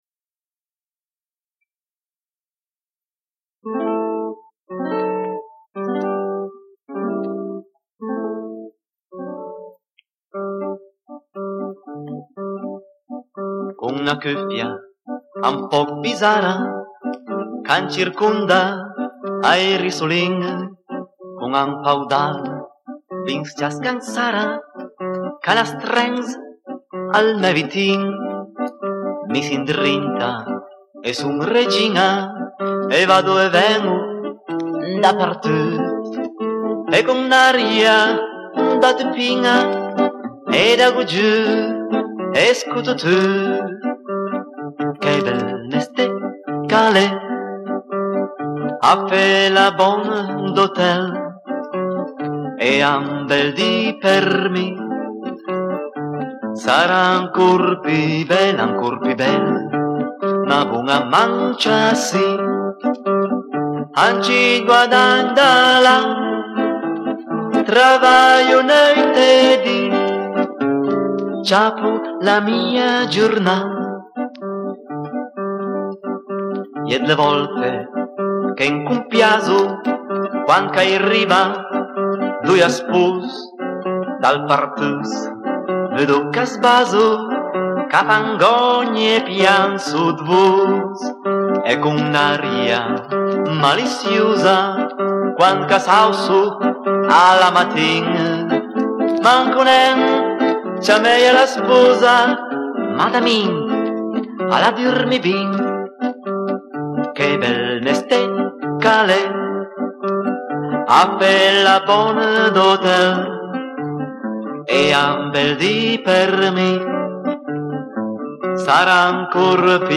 Canzone popolare